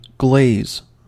Ääntäminen
IPA: /ɡleɪz/